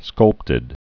(skŭlptĭd)